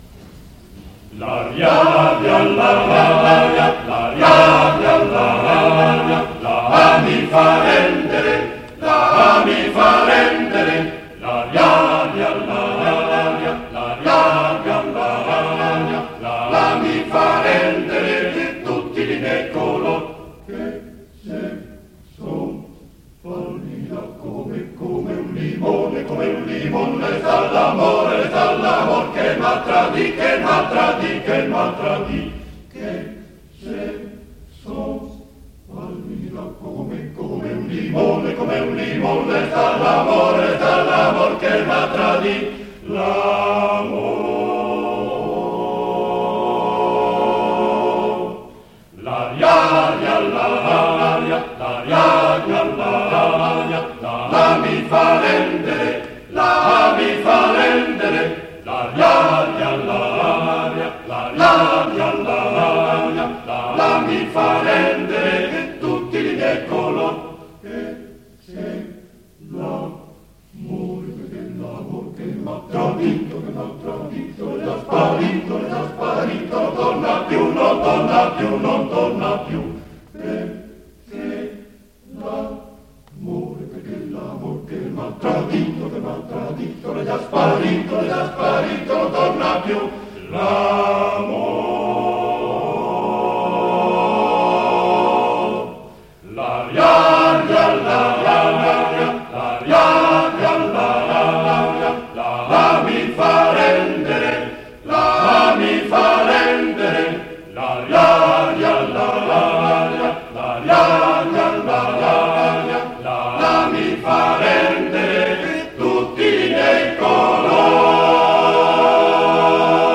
A.8.1.8 - L'aria de la campagna (Coro della SAT, Torino, Conservatorio G. Verdi, 8 dicembre 1956)